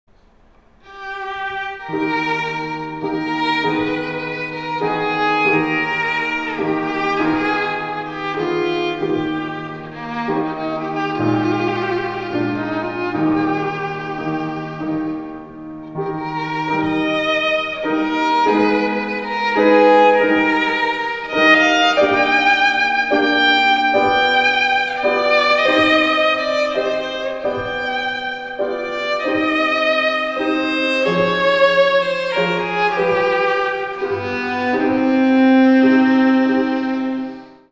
ヴィオラ